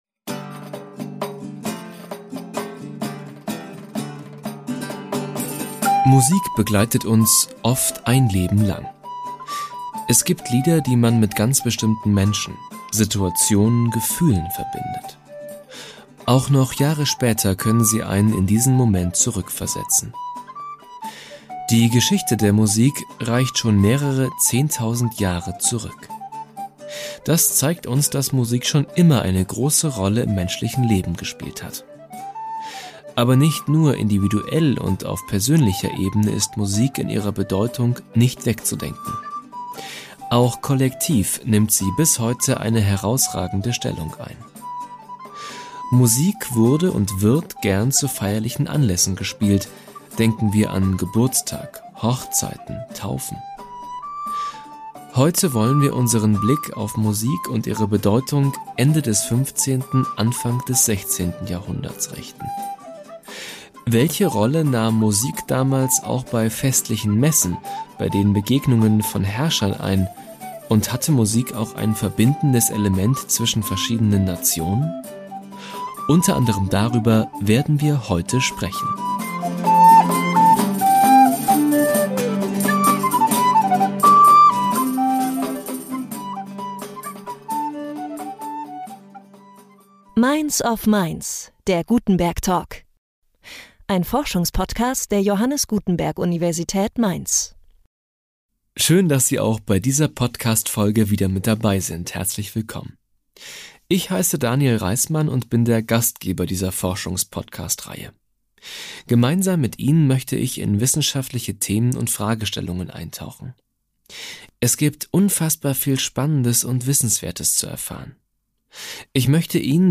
In diesem Podcast stehen die Musik und ihre Bedeutung für festliche Messen oder die Herrschenden Ende des 15., Anfang des 16. Jahrhunderts im Mittelpunkt des Gesprächs